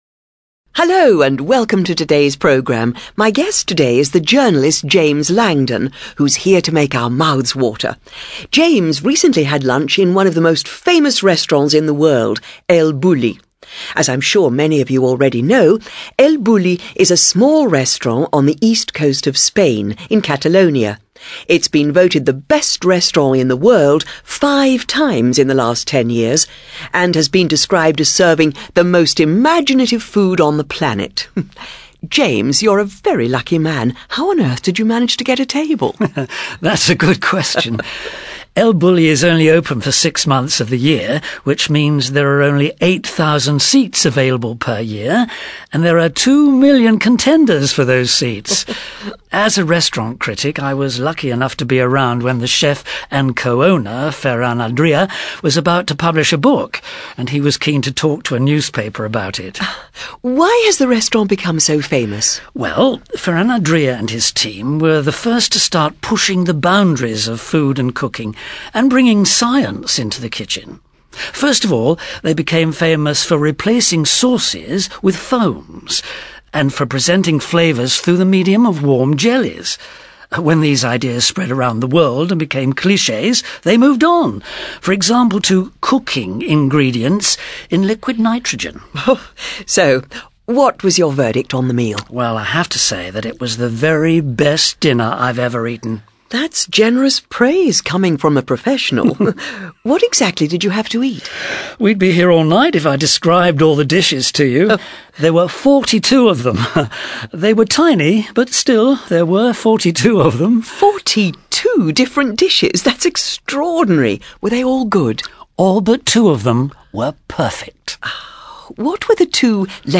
ACTIVITY 43: You are going to listen to a radio interview with a restaurant critic about a meal he has eaten recently.